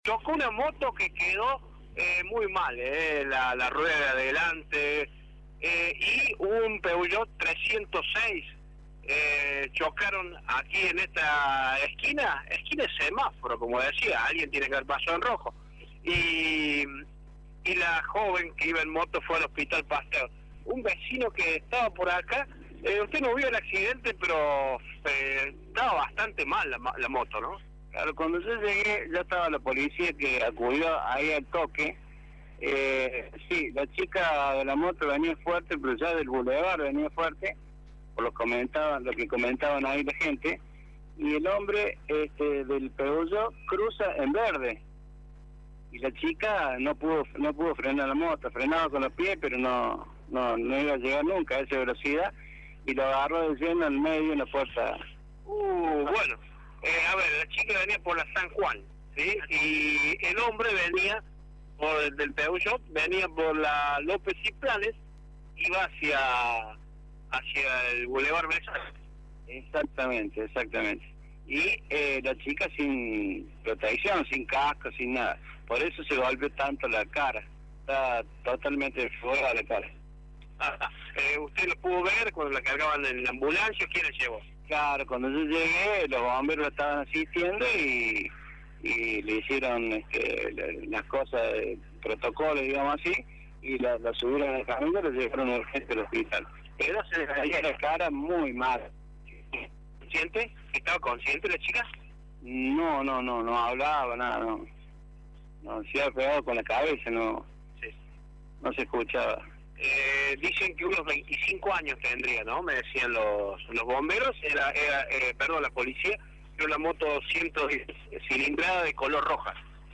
Un testigo habló con Radio Show.